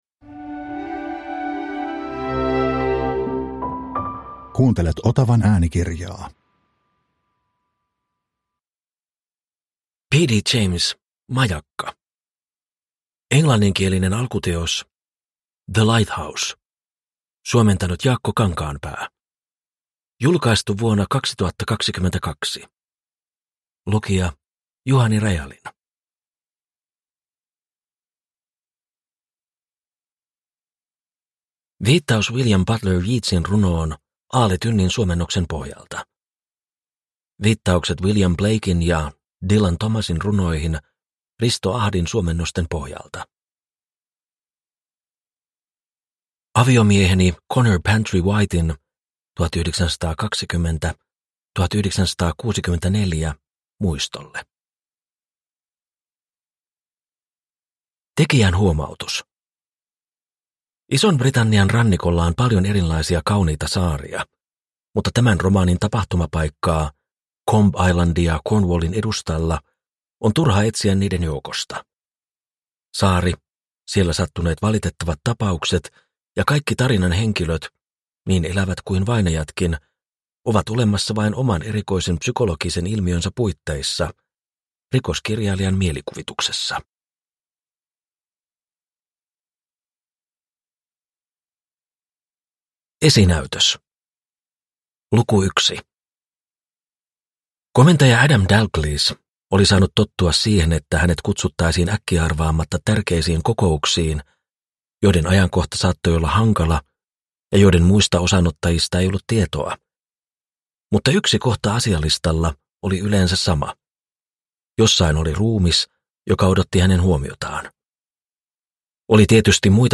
Majakka – Ljudbok – Laddas ner